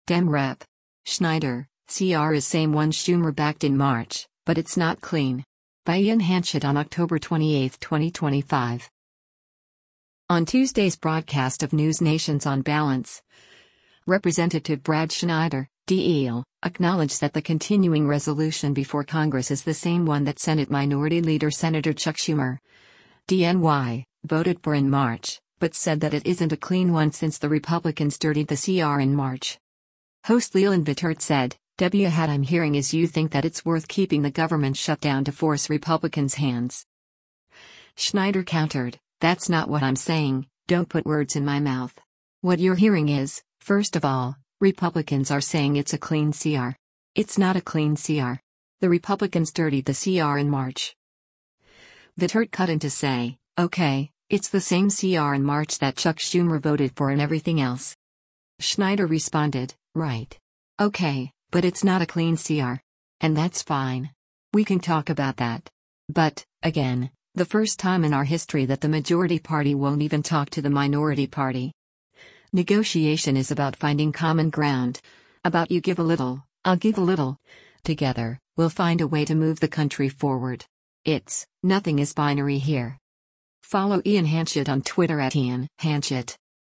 On Tuesday’s broadcast of NewsNation’s “On Balance,” Rep. Brad Schneider (D-IL) acknowledged that the continuing resolution before Congress is the same one that Senate Minority Leader Sen. Chuck Schumer (D-NY) voted for in March, but said that it isn’t a clean one since “The Republicans dirtied the CR in March.”
Vittert cut in to say, “Okay, it’s the same CR in March that Chuck Schumer voted for and everything else.”